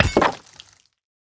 axe-mining-stone-6.ogg